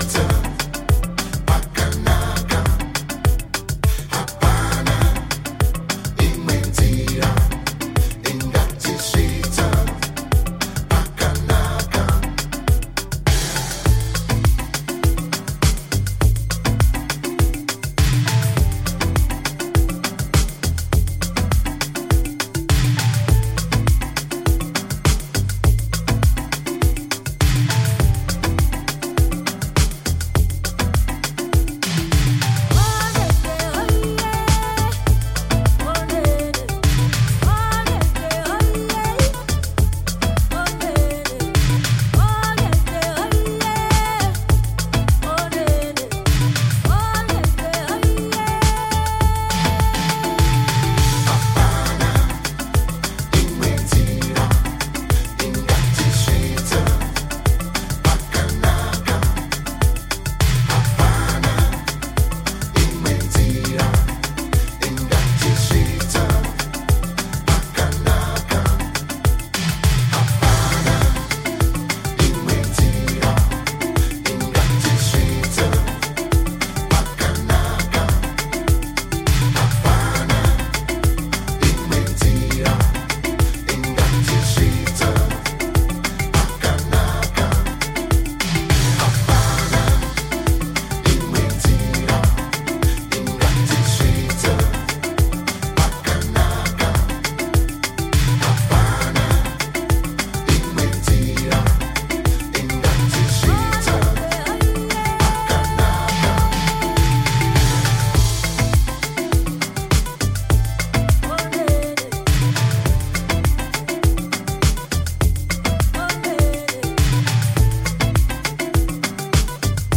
ワールド・ミュージック的な雰囲気が最高のグルーヴィーなディスコ〜ハウス・チューン！